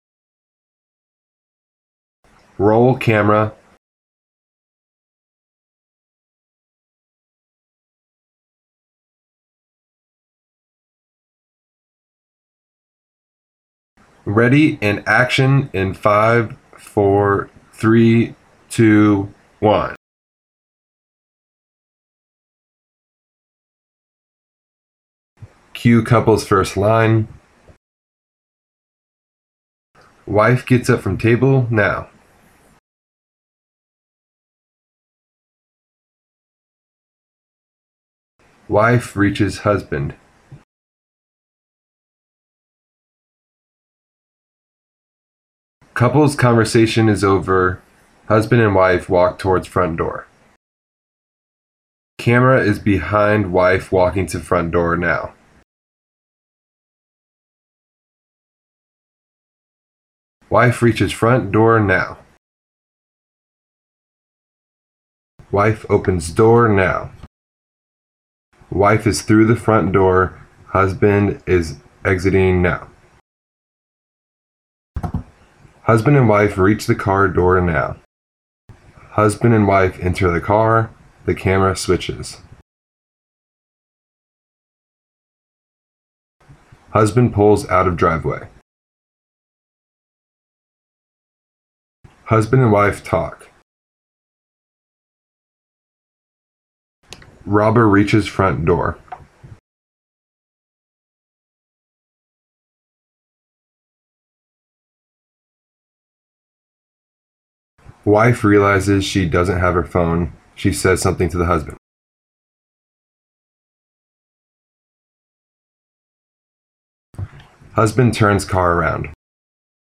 Anyway, I read aloud the actions of the characters.
Here’s both clips of me giving directions for the actors while we shot. I made a different one for each angle.
Split-Life-SATO-48-Voice-Planning_Husband-and-Wife.mp3